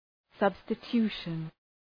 Shkrimi fonetik{,sʌbstə’tu:ʃən}